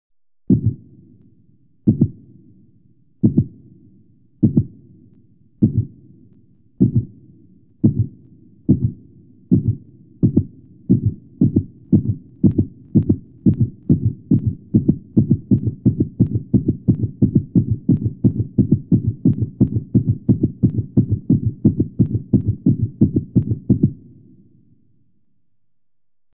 دانلود صدای ضربان قلب انسان مضطرب با ریتم نامنظم از ساعد نیوز با لینک مستقیم و کیفیت بالا
جلوه های صوتی